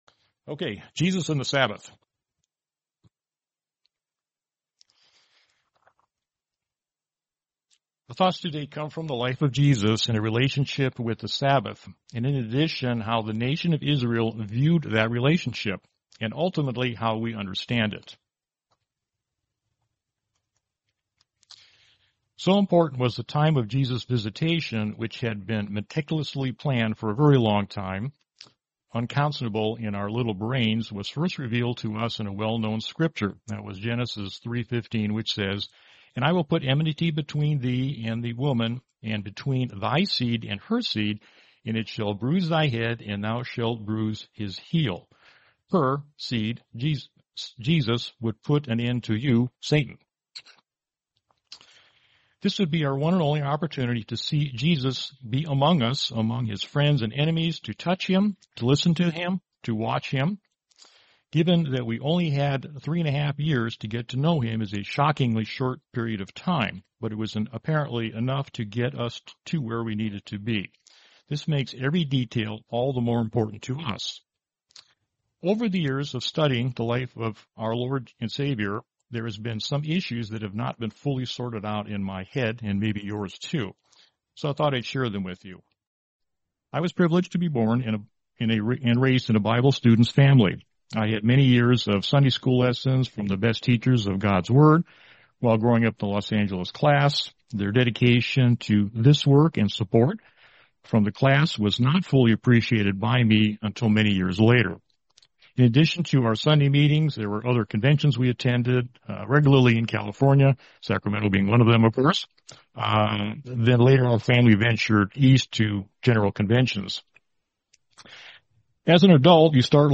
Series: 2026 Sacramento Convention